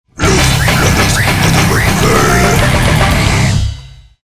boost bass
guitar
drums
vocals